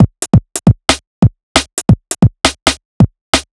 Killa Break 1 135.wav